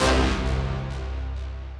HIT 8 .wav